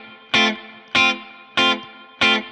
DD_StratChop_95-Fmaj.wav